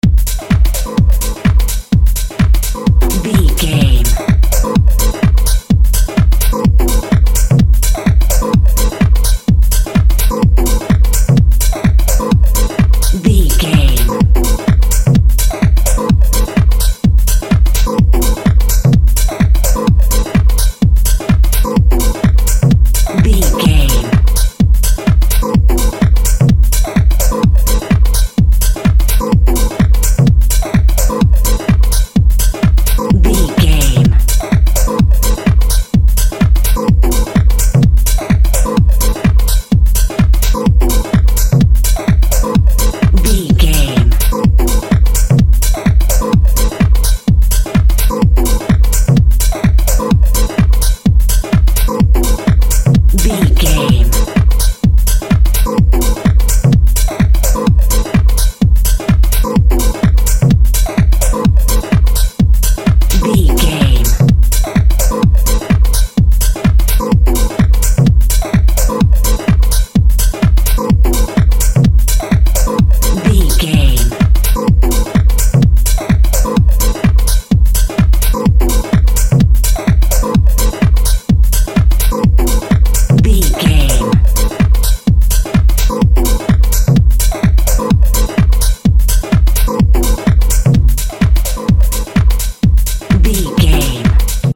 Atonal
uplifting
lively
futuristic
hypnotic
industrial
driving
drum machine
synthesiser
house
techno
electro house
synth lead
synth bass